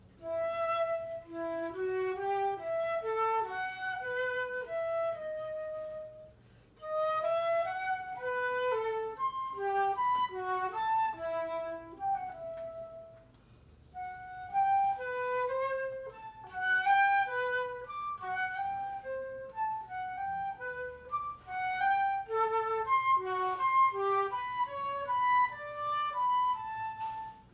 Solo
Extrait lors d’une lecture & concert, Gazette Café, Montpellier, juin 2023